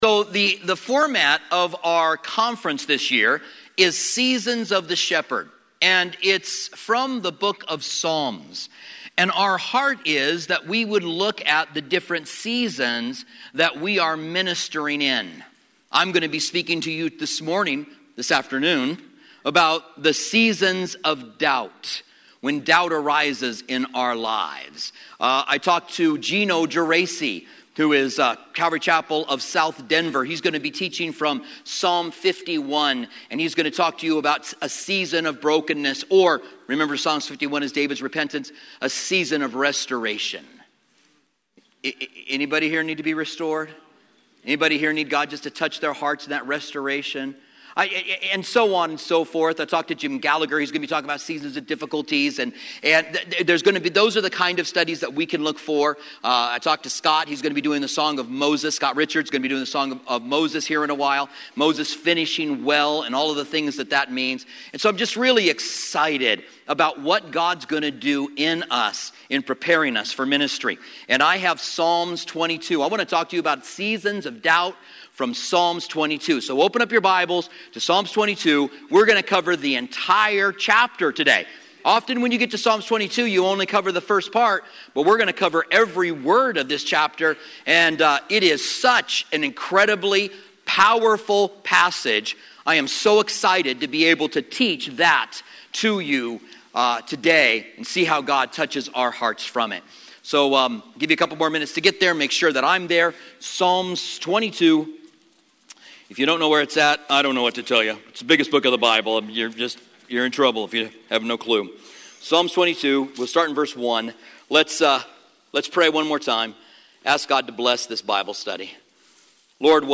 2017 SW Pastors and Leaders Conference